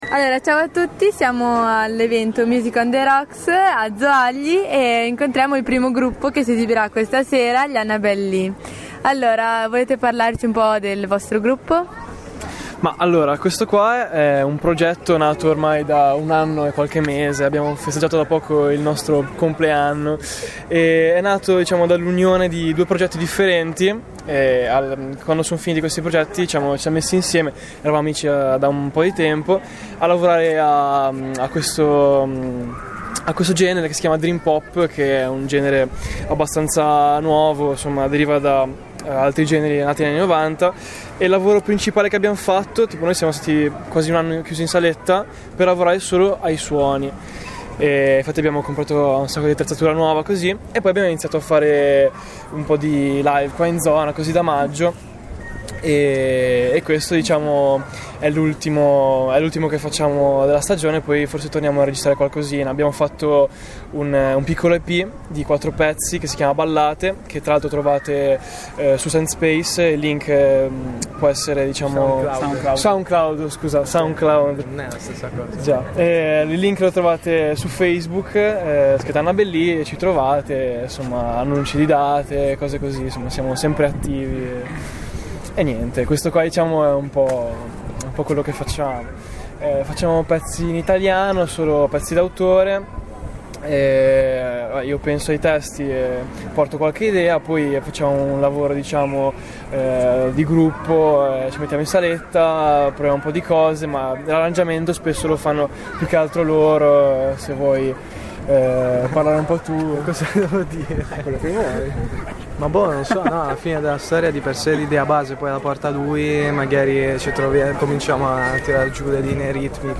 play_circle_filled Intervista a Annabel Lee Radioweb C.A.G. di Rapallo Gruppo musicale intervista del 29/08/2013 Intervista al gruppo rock "Annabel Lee" prima del concerto tenutosi a Zoagli il 29 Agosto. Gli Annabel Lee sono un gruppo rock emergente del Tigullio che propone una propria produzione musicale del tutto originale. Il gruppo si racconta ai microfoni della nostra TWR.